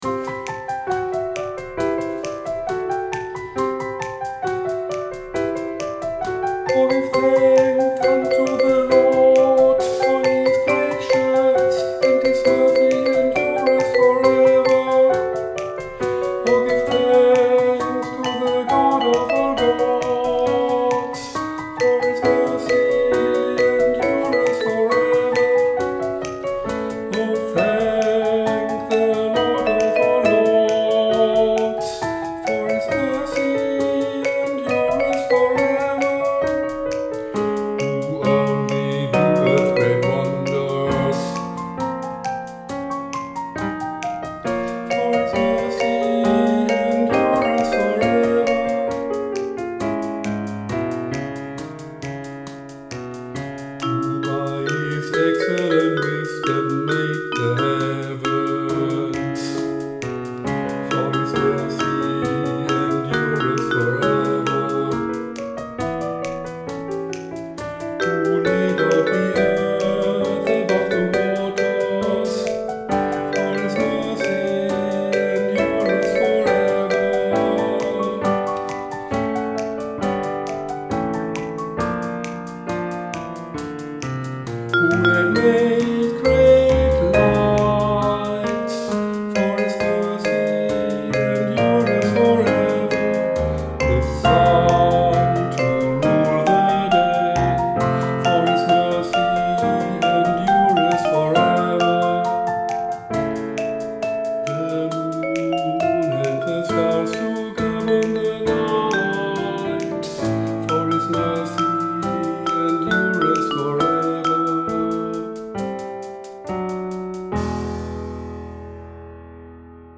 O give thanks | Schlusskonsonanten korrekt gesungen - langsam